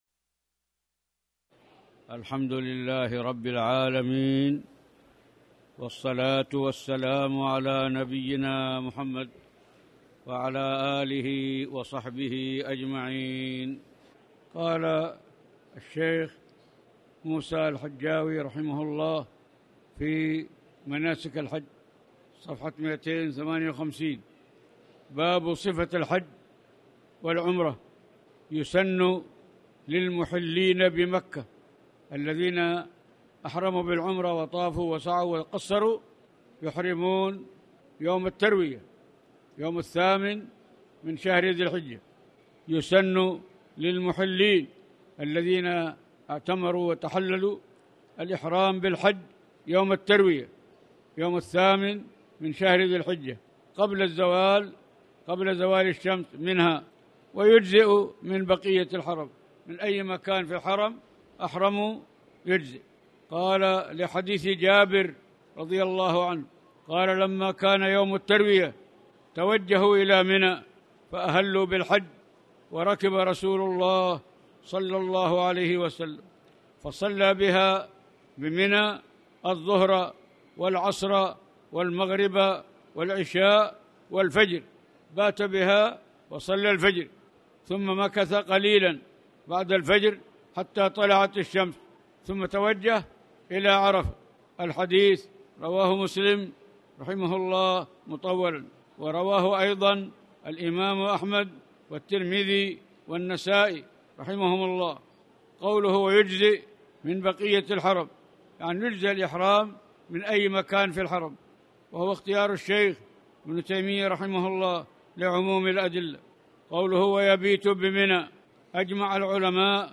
تاريخ النشر ٢٦ ذو القعدة ١٤٣٨ هـ المكان: المسجد الحرام الشيخ